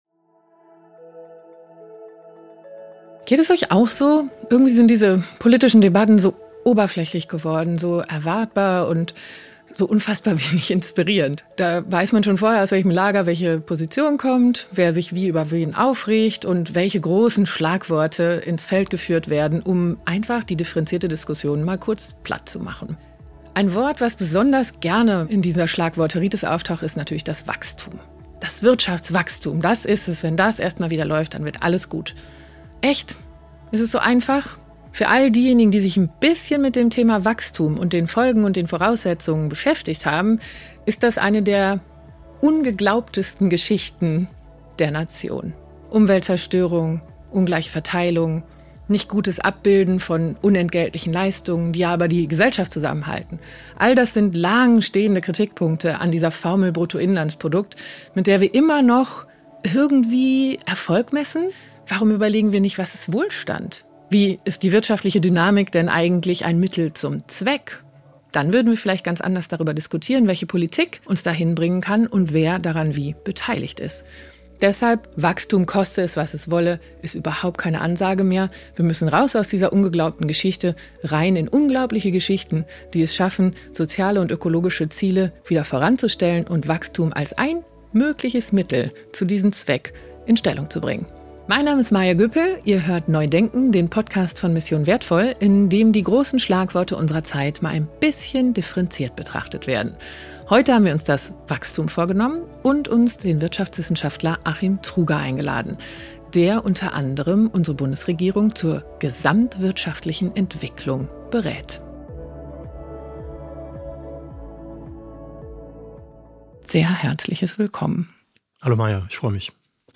Truger und die Moderatorin Maja Göpel bemängeln, dass die Debatten über Wirtschaftswachstum oft oberflächlich und vorhersehbar sind.